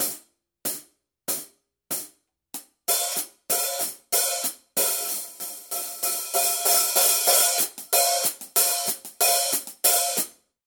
Paiste 14" PST 7 Hi-Hat Cymbals | Nicko's Drum One
PST7 is an affordable line for drummers who like a traditional sound, look and feel.
The result of Paiste’s precise Swiss manufacturing is a warm and clear overall sound and by offering three weight classes.